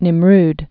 (nĭm-rd)